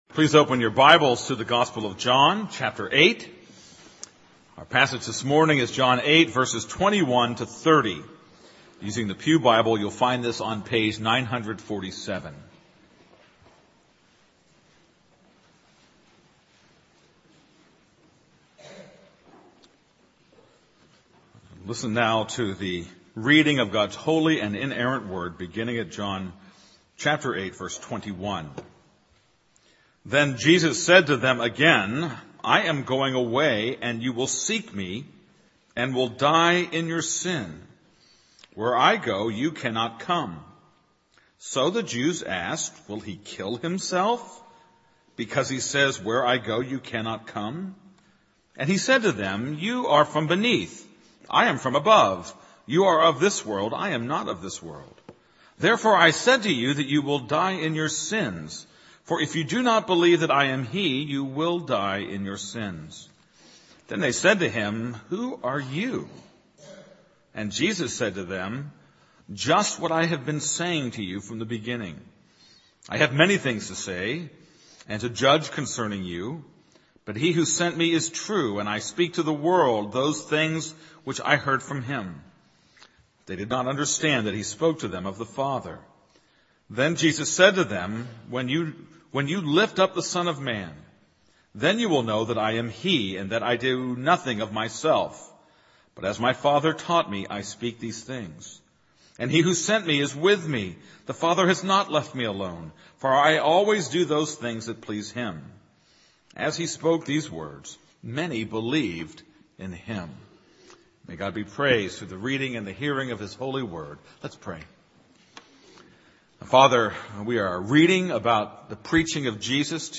This is a sermon on John 8:21-30.